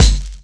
Kicks
RawKik07_Du7.wav